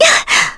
Frey-Vox_Damage_kr_01.wav